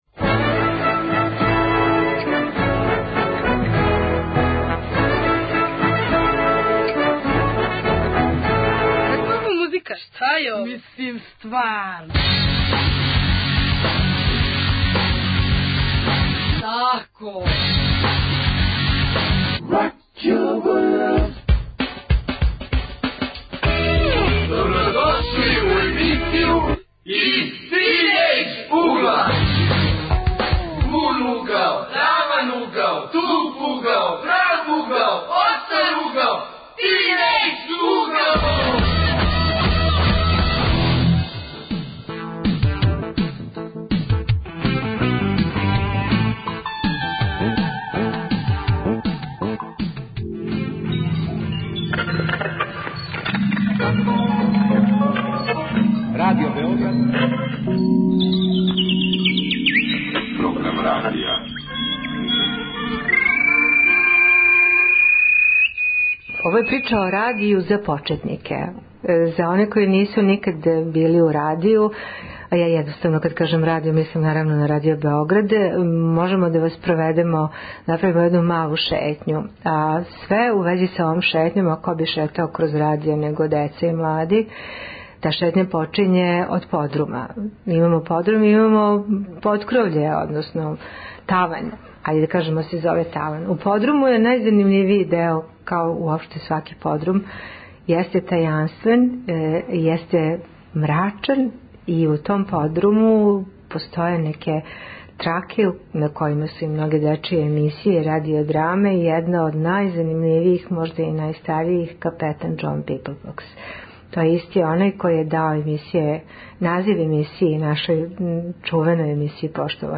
Тема: млади и радио, гости - тинејџери